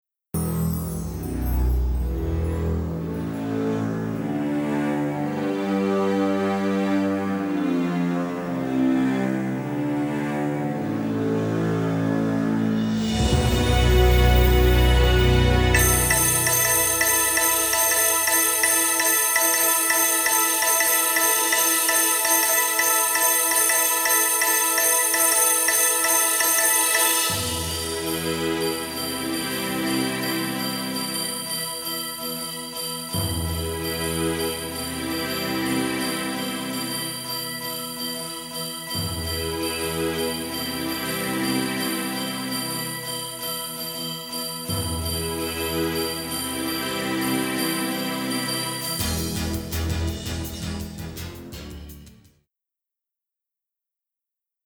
sci-fi soundtrack